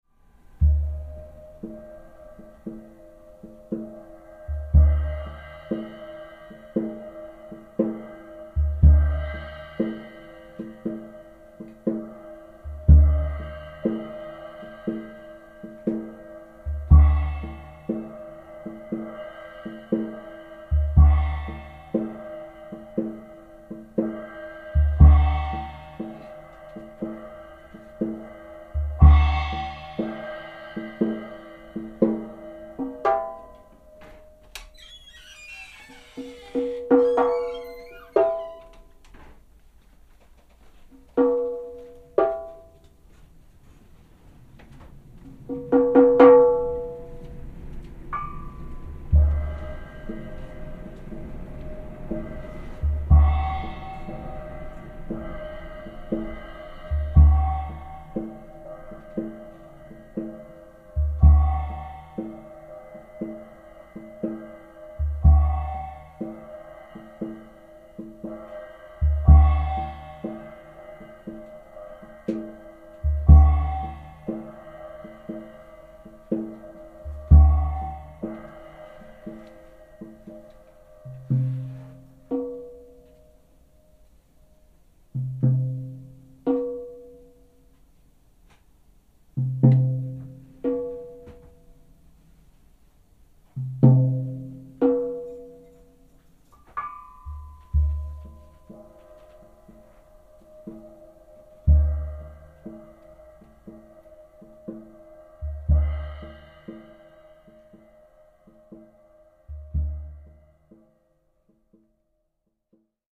ハーモニックなパーカッションが描き出す繊細でドラマティックな音像
drums, percussion